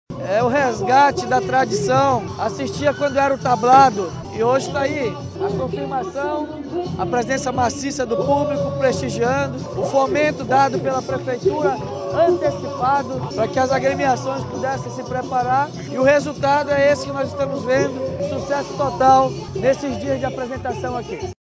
SONORA-DAVID-ALMEIDA-Prefeito-_.mp3